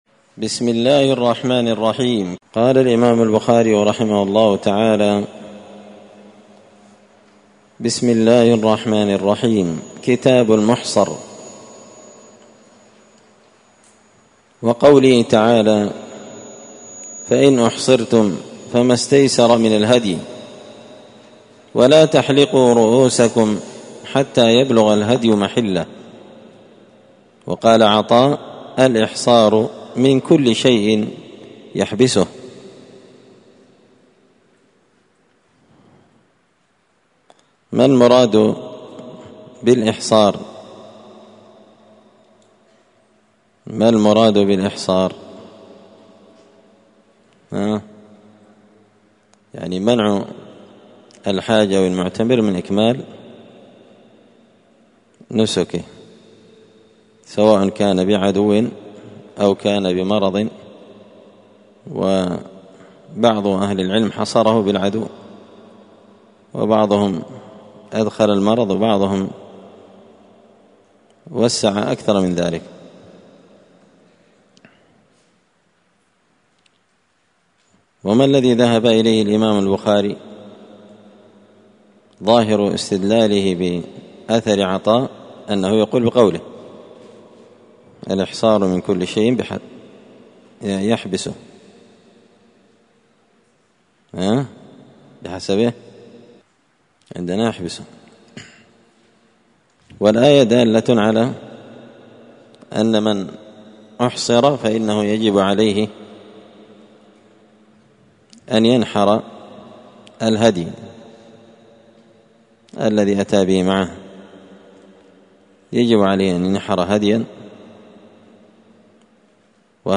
مسجد الفرقان قشن المهرة اليمن